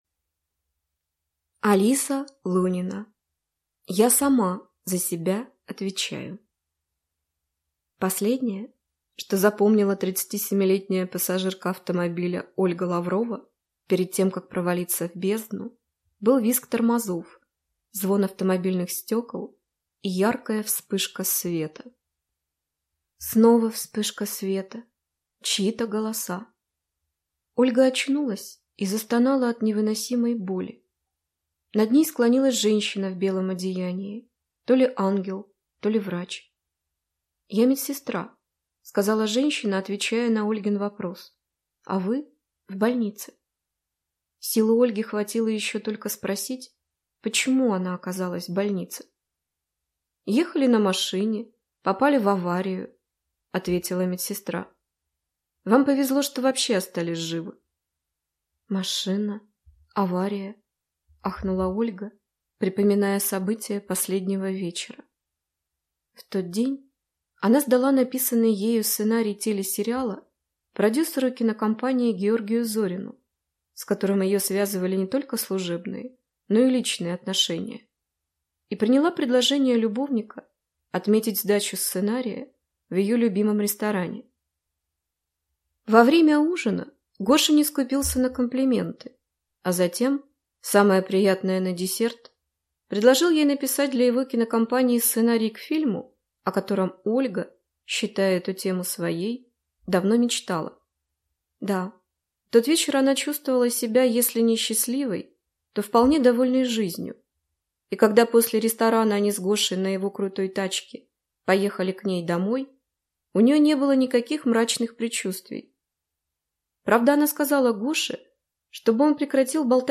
Аудиокнига Я сама за себя отвечаю | Библиотека аудиокниг